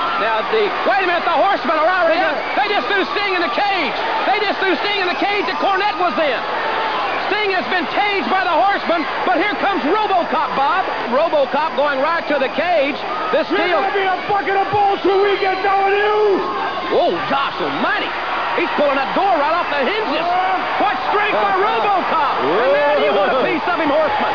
So he came to Capital Combat as Sting’s bodyguard.
good ol’ JR who was calling the action.